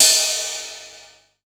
Brush Ride3.wav